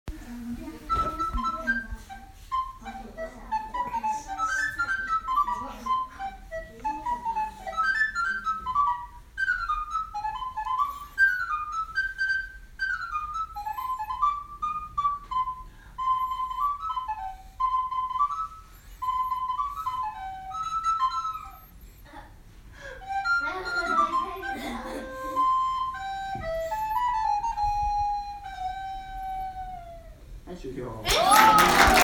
２月６日（月）３年生がリコーダー奏者の方から講習を受けました。
模範の演奏